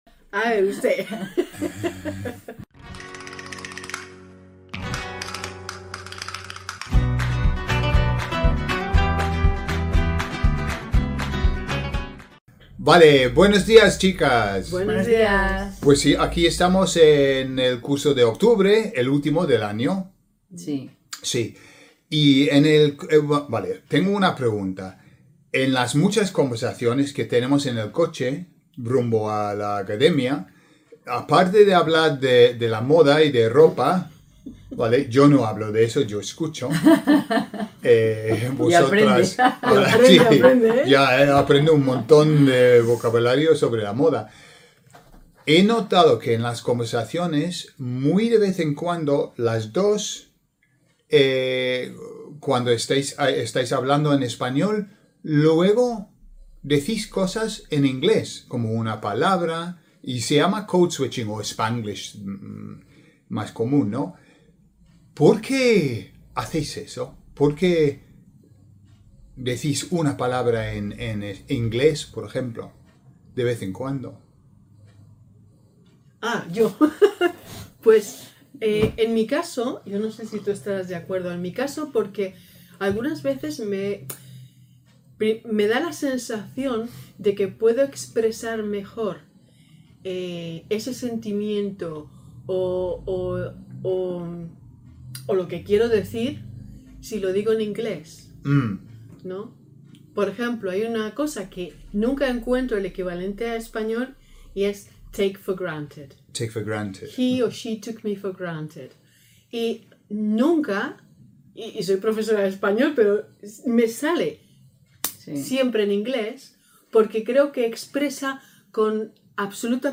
In this fun conversation